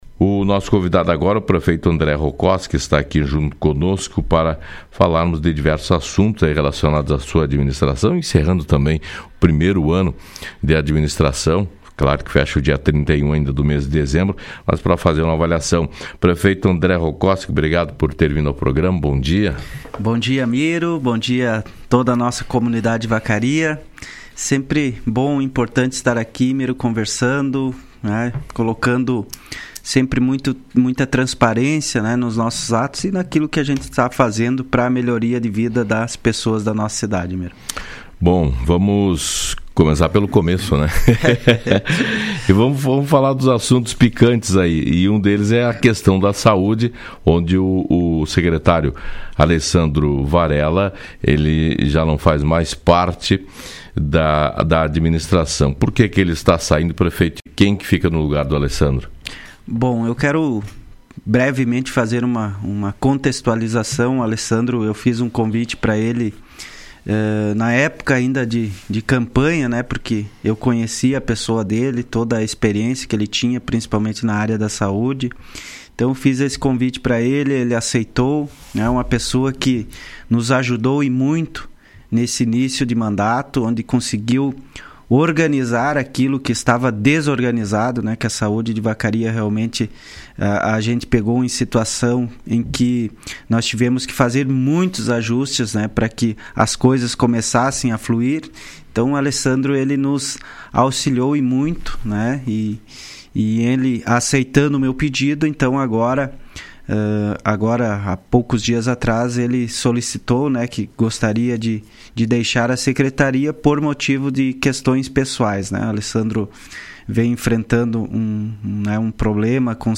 O prefeito de Vacaria, André Rokoski, participou do programa Fala Cidade desta segunda-feira. Durante a entrevista, ele falou sobre diversos assuntos, inclusive trazendo esclarecimentos importantes sobre a troca de titular na secretaria da saúde.